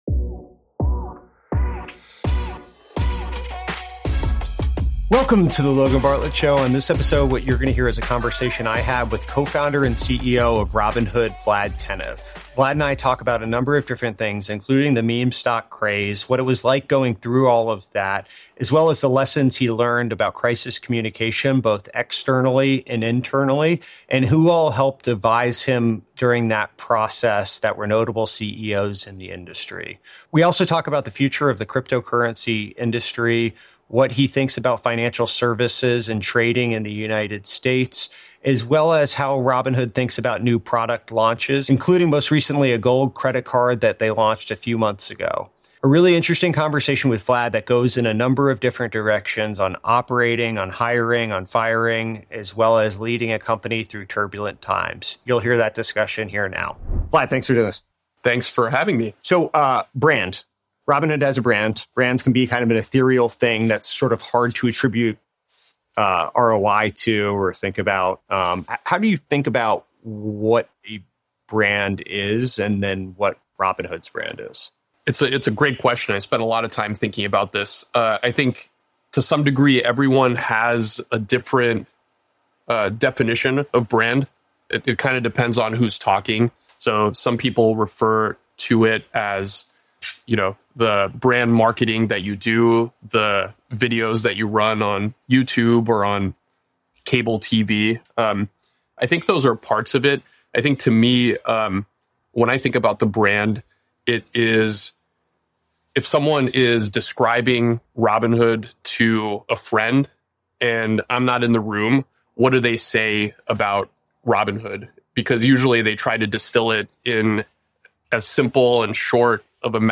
Guest Vlad Tenev